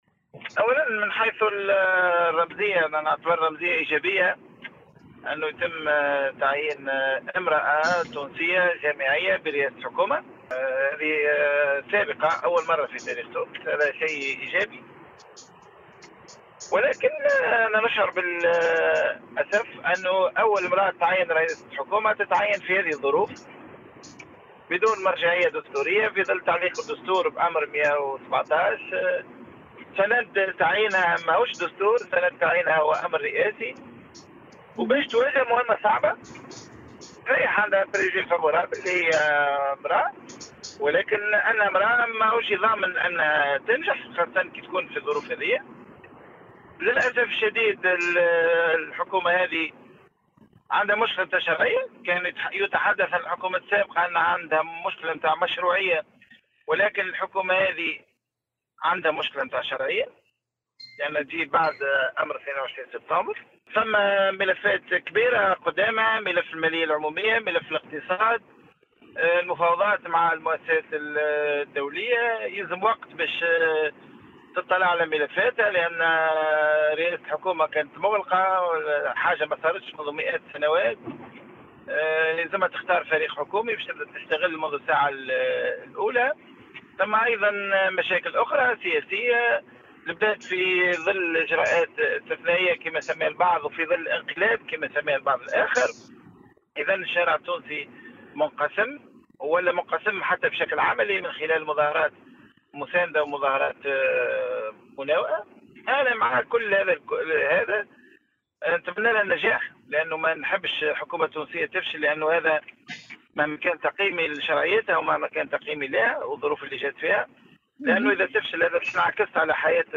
Dans une déclaration accordée à Tunisie numérique l’ancien dirigeant nahdoui Samir Dilou s’est déclaré désolé de voir la première femme tunisienne au poste de chef du gouvernement désignée « alors que la constitution est suspendue ».